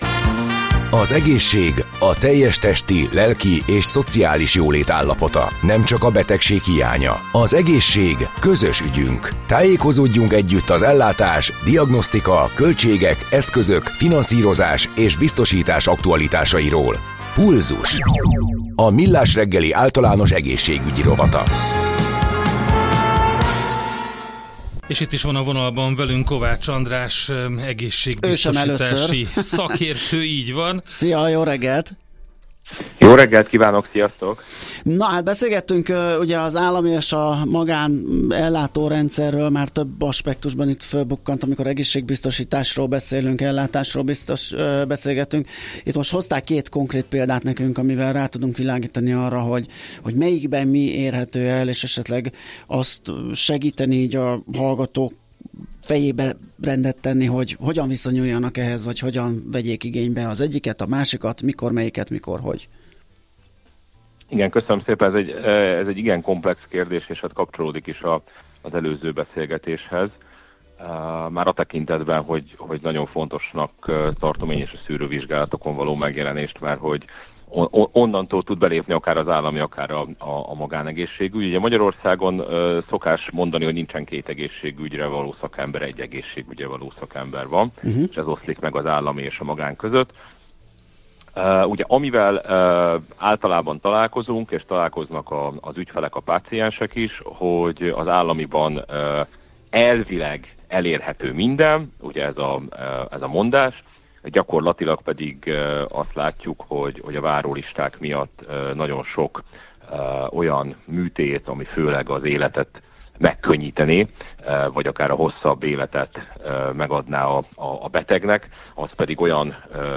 interjúban